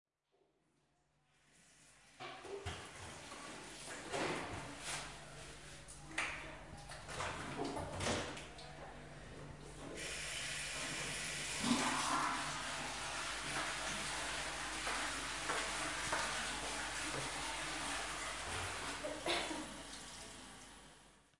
描述：公共浴室的环境声音。
Tag: 公共 ENVIROMENTAL 冲洗 冲洗 浴室 厕所 声音